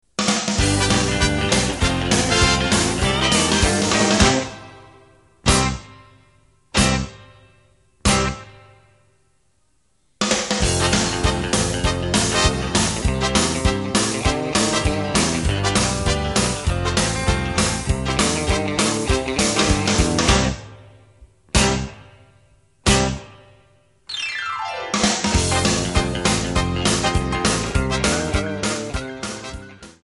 Backing track files: 1950s (275)